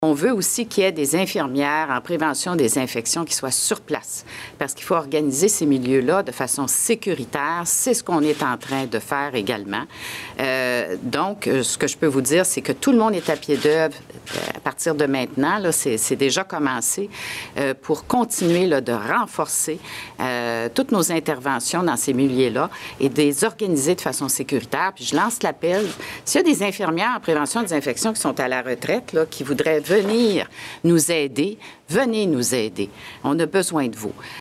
La ministre de la Santé, Danielle McCann, explique que des infirmières en prévention des infections seront aussi envoyées dans ces établissements. Elle lance un appel aux infirmières à la retraite :